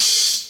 Open Hats
Lofi_Oh1.wav